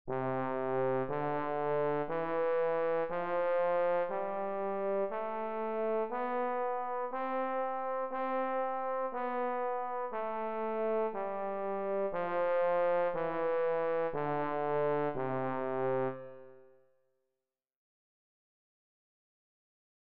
Lo spostamento della coulisse provoca la variazione della lunghezza del tubo producendo quindi le diverse note.
Trombone
Trombone.mp3